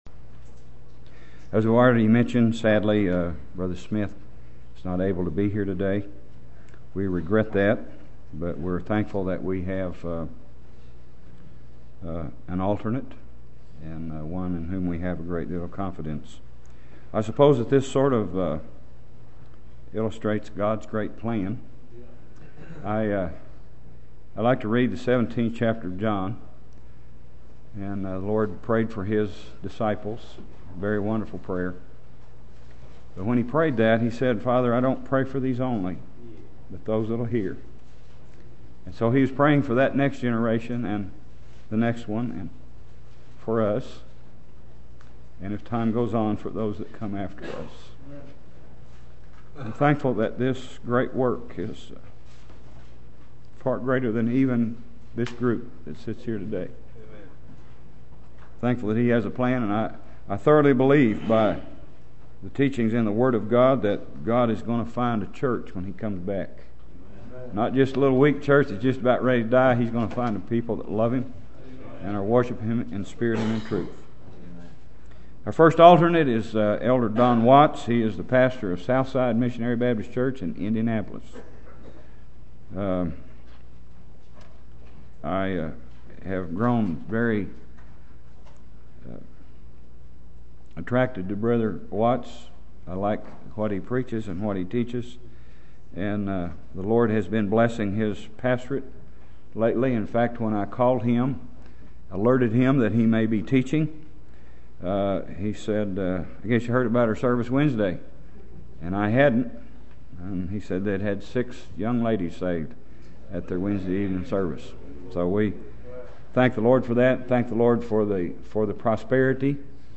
Lesson 1 from the 2004 session of the Old Union Ministers School.